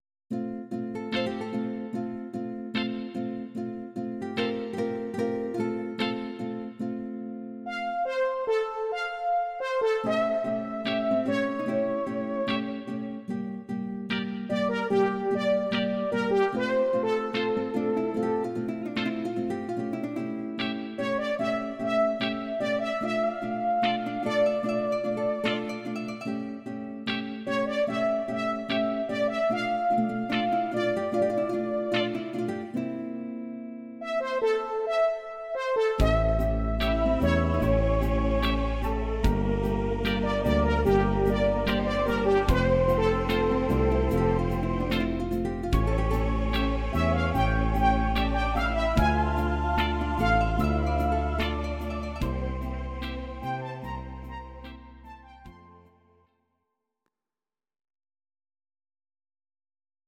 Audio Recordings based on Midi-files
Pop, Oldies, Ital/French/Span, 1960s